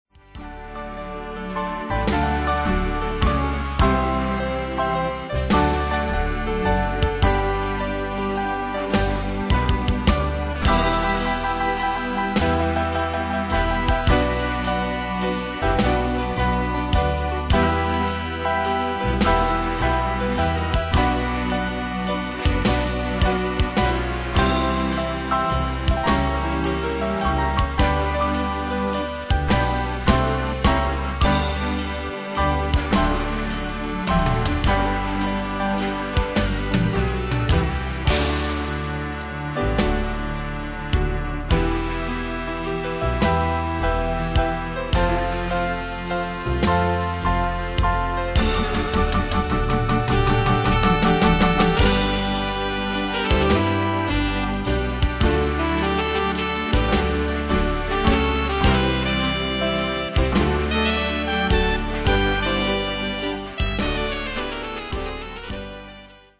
Bandes et Trames Sonores Professionnelles
Pro Backing Tracks